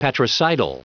Prononciation du mot patricidal en anglais (fichier audio)
Prononciation du mot : patricidal